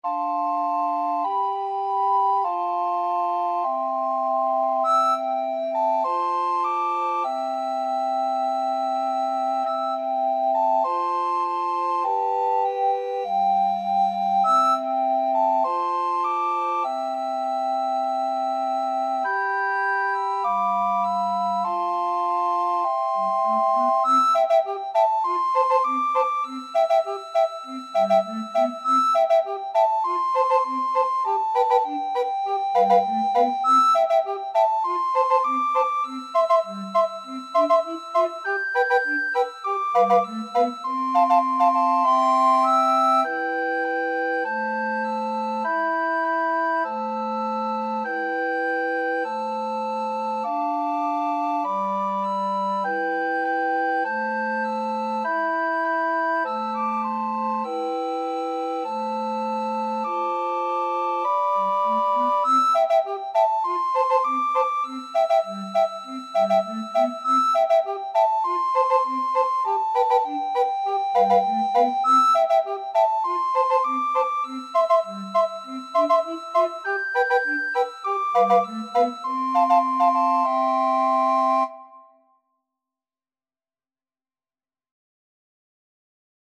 Soprano RecorderAlto RecorderTenor RecorderBass Recorder
Moderato =c.100
4/4 (View more 4/4 Music)
Recorder Quartet  (View more Easy Recorder Quartet Music)